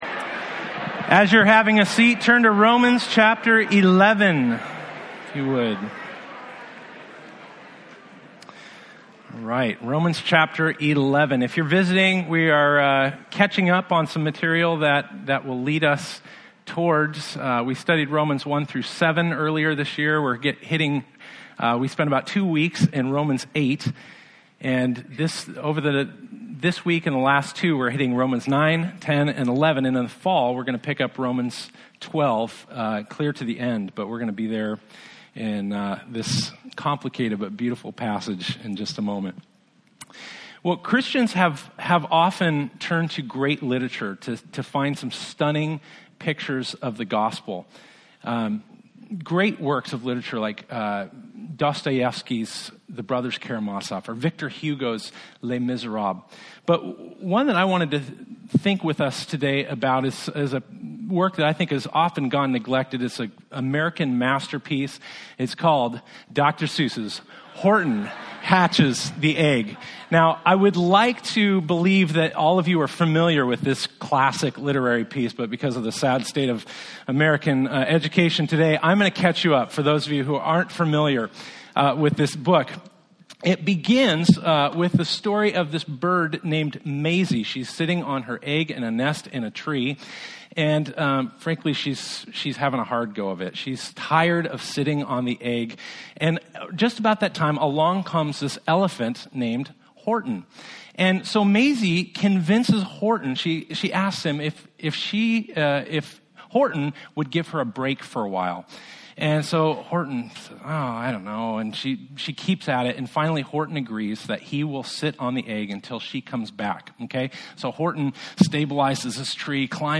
Romans 11:1-36 Service Type: Sunday Topics